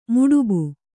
♪ muḍubu